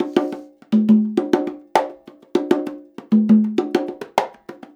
100 CONGAS18.wav